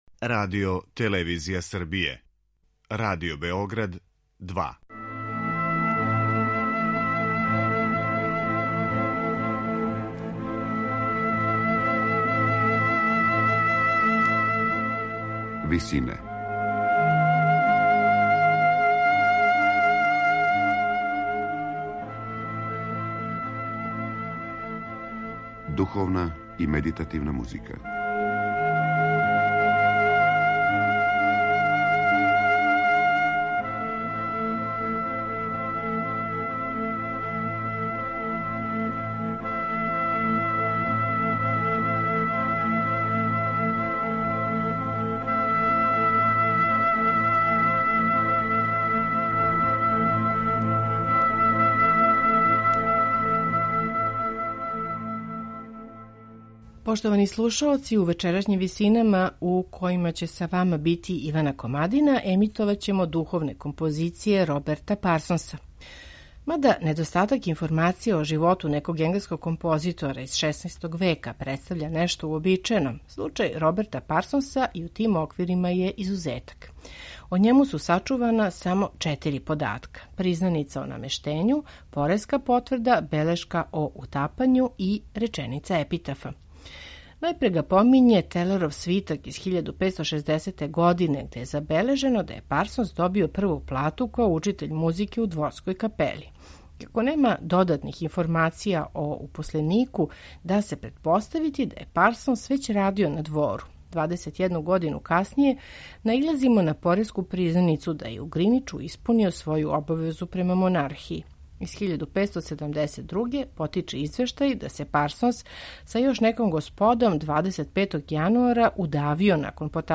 Роберт Парсонс: Духовни комади
представљамо медитативне и духовне композиције аутора свих конфесија и епоха